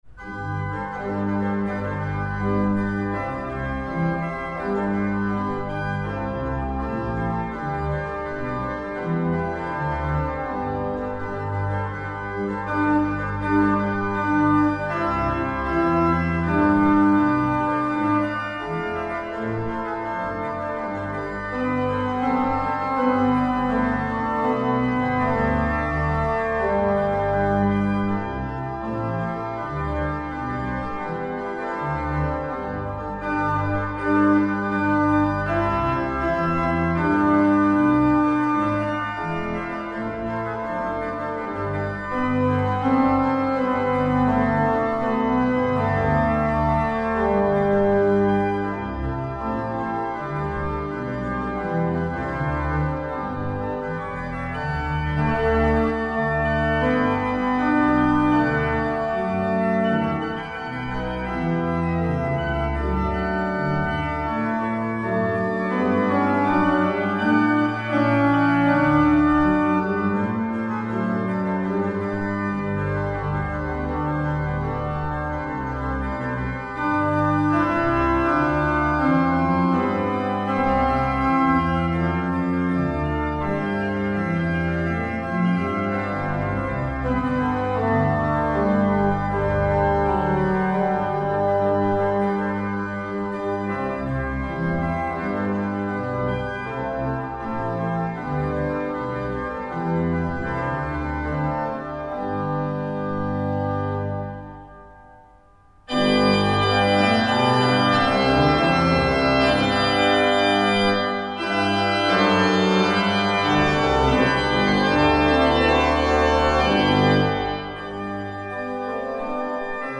Nürtingen, St. Johannes, ALBIEZ-Orgel
Katholische Pfarrkirche St. Johannes, 72622 Nürtingen